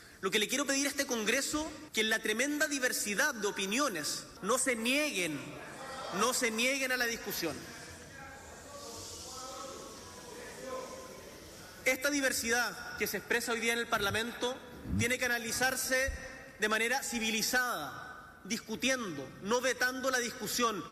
cuna-tl-discurso-boric-aborto.mp3